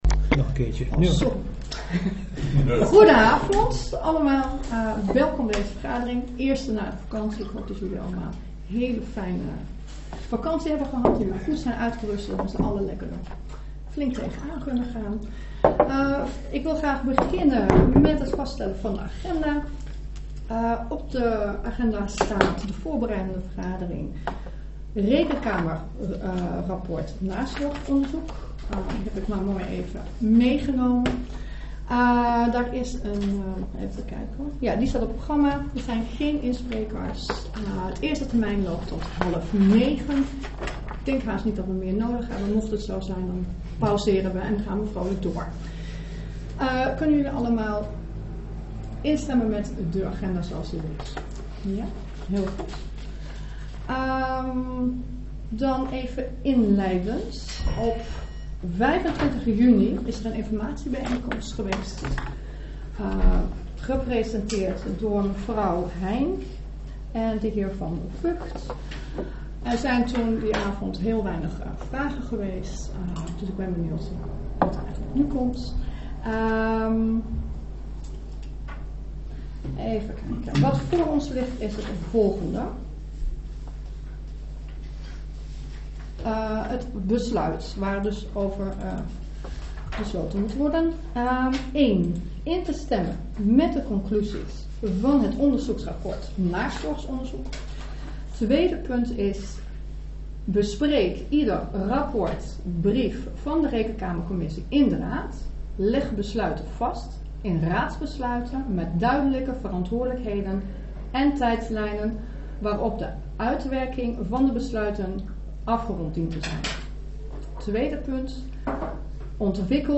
Locatie gemeentehuis Elst Voorzitter mevr. E. Patelias-Pieks Toelichting Voorbereidende vergadering rekenkamerrapport "Nazorgonderzoek" Agenda documenten 18-08-21 OPname 3.